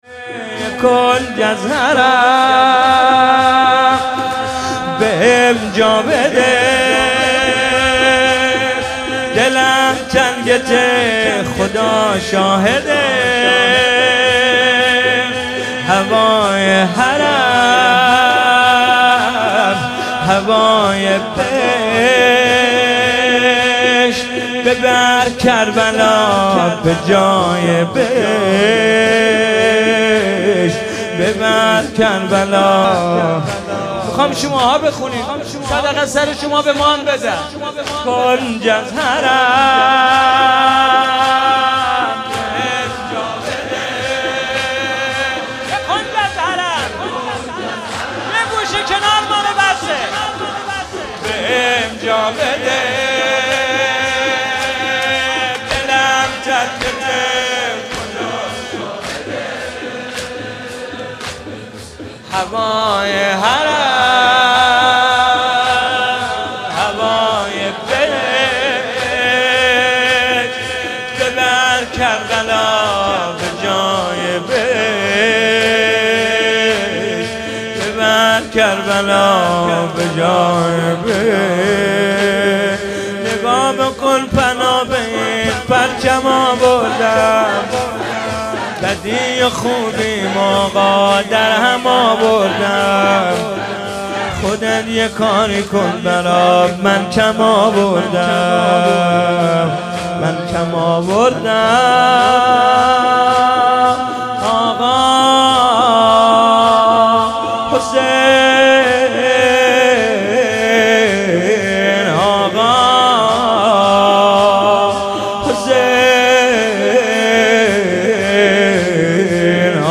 مذهبی